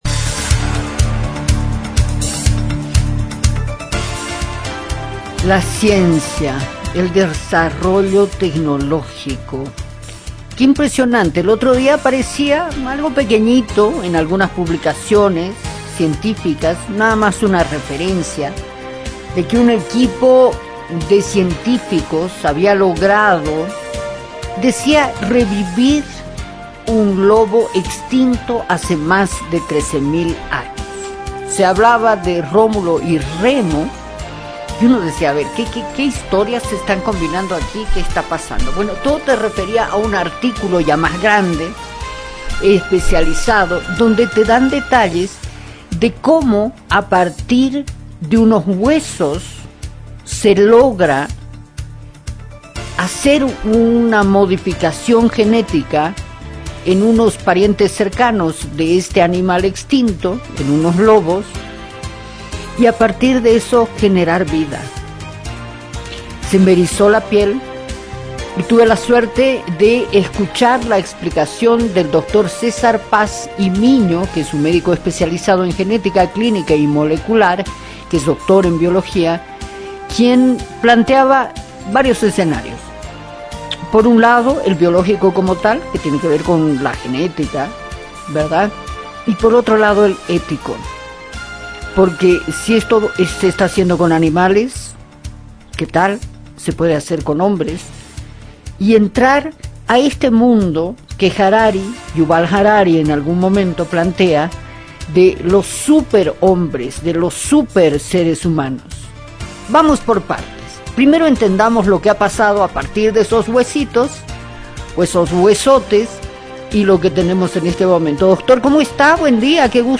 Hoy, en este episodio, exploramos cómo un equipo de científicos está reviviendo a una especie de lobo extinto, desafiando las leyes de la biología, la ética… y quizás, del tiempo mismo. Nuestro invitado, desde Quito – Ecuador